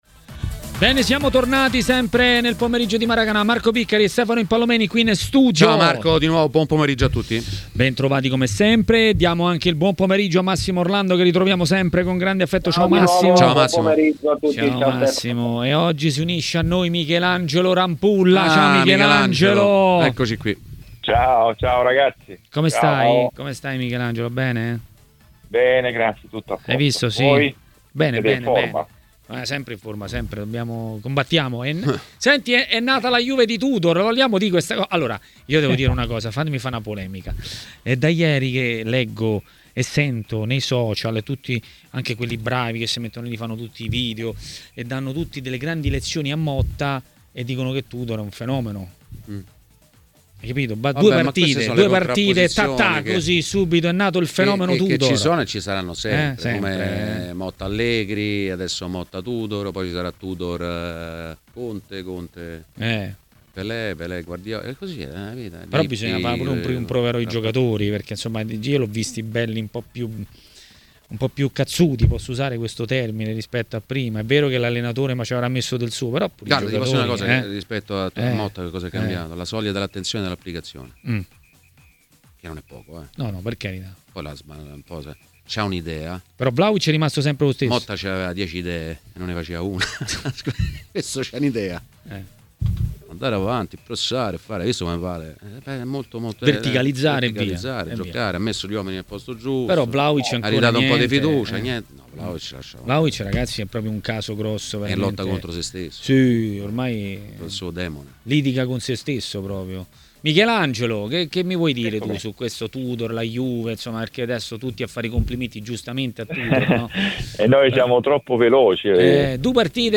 A commentare la Juventus a Maracanà, nel pomeriggio di TMW Radio, è stato l'ex portiere Michelangelo Rampulla.